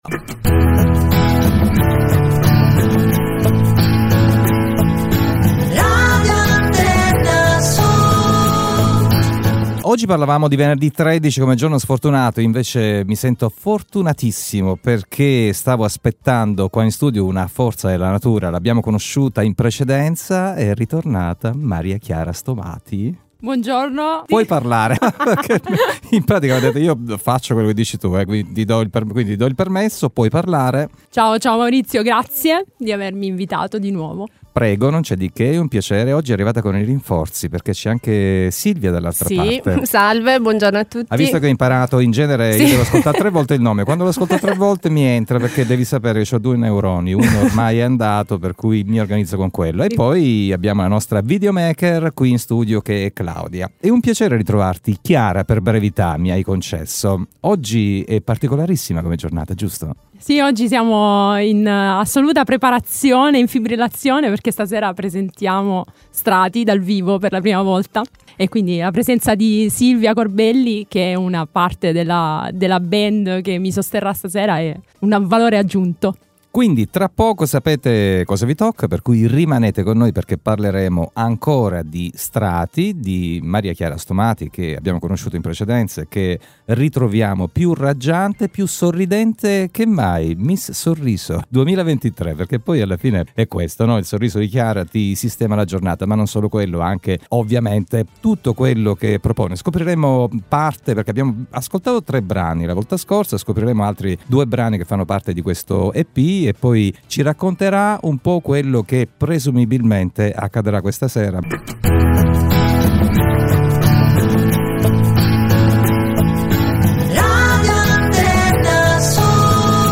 Una piacevole lunga chiacchierata
in studio